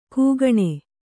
♪ kūgaṇe